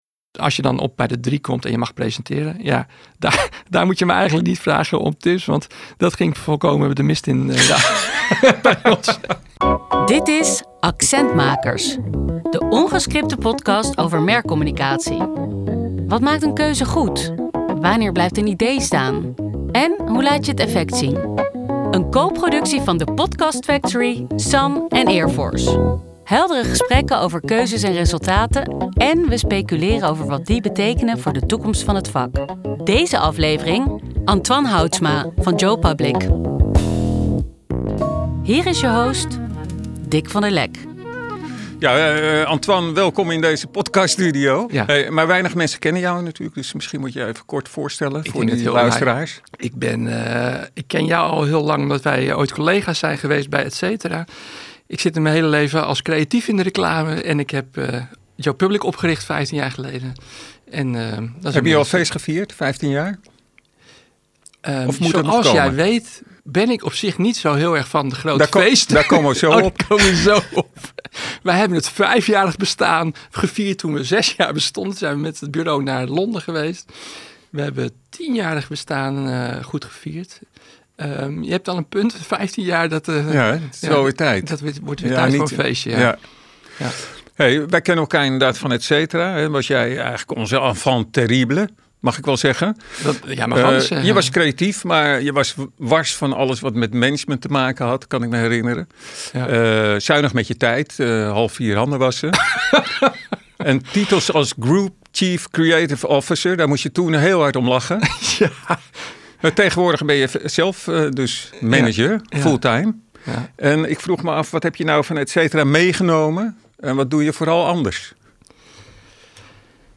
In dit gesprek gaat het van de paarse krokodil naar iets veel actuelers: AI.